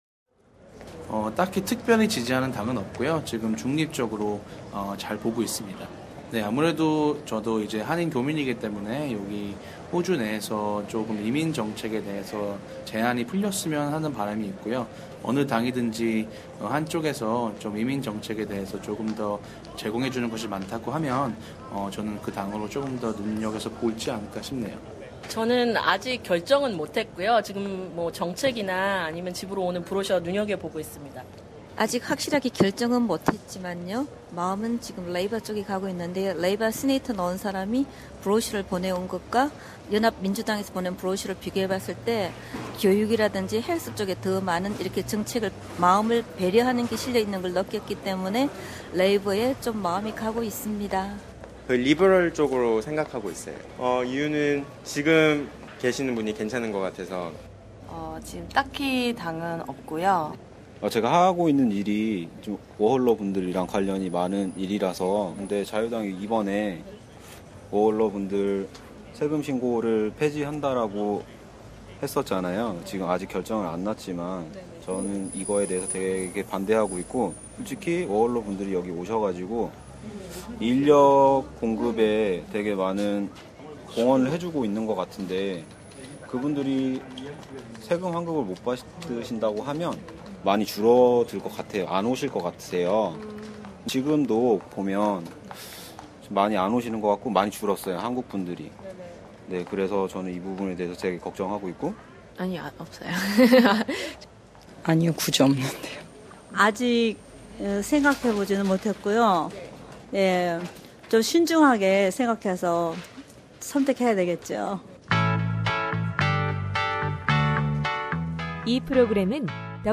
Korean Program has met a variety of Korean speaking voters on the street to hear about their say on the upcoming election.